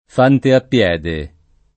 fante a piè [f#nte a ppL$+] o fante a piede [
f#nte a ppL$de] locuz. m. — voce ant. per «soldato di fanteria»: con XX cavalli e CCC fanti a piè [kon v%nti kav#lli e ttre©$nto f#nti a ppL$] (Compagni); gli capitò alle mani uno ch’era come uno per fante a piede [